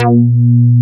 FKBASSE3.wav